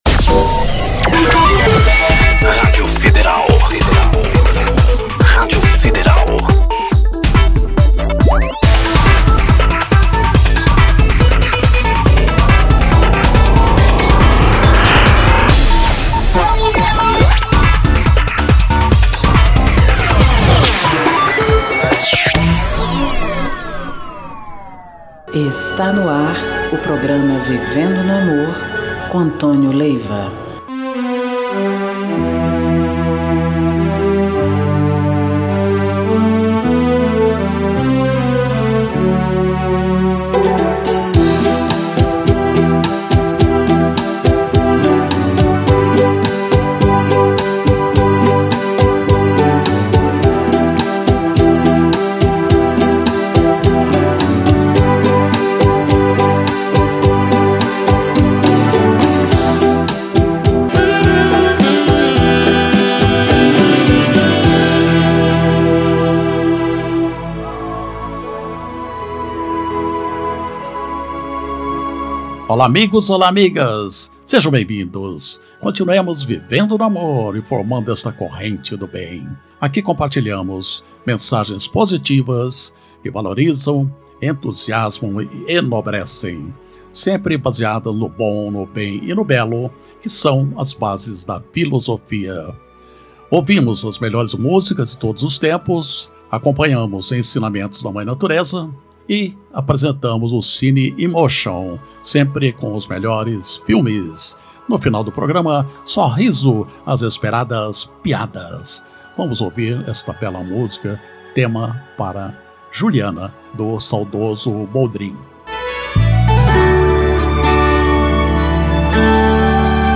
MENSAGENS E MÚSICAS